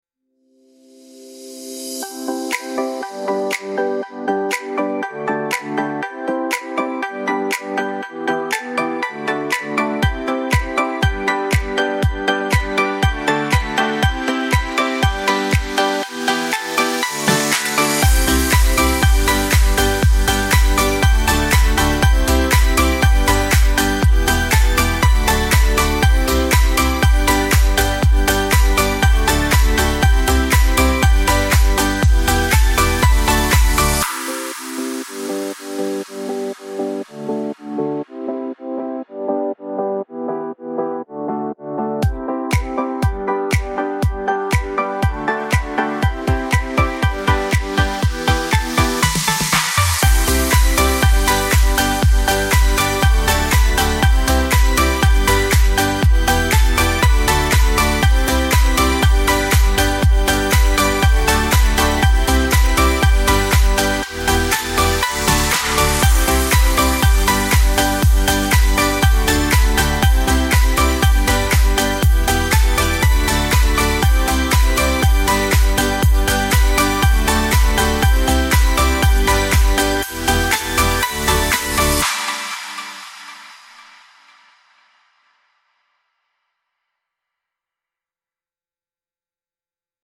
bright and upbeat celebration music with claps and cheerful synths